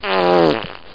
toot_wet.ogg